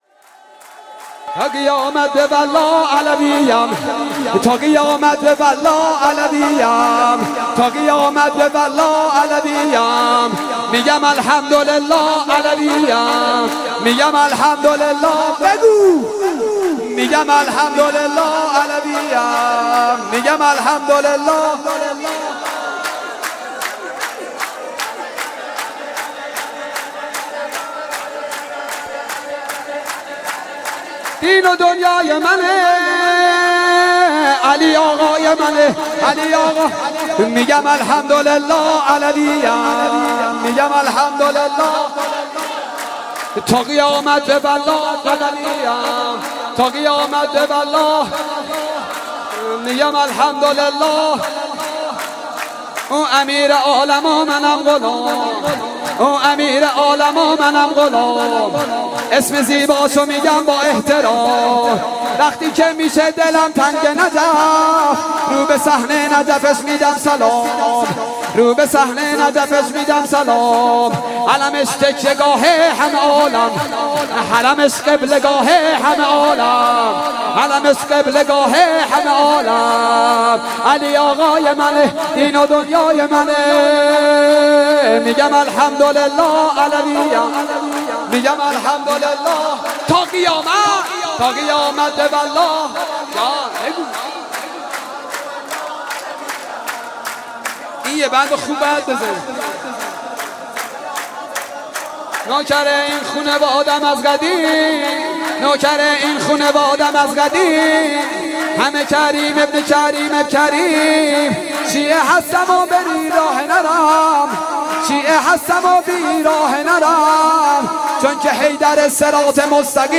میلاد امام حسن عسکری (ع)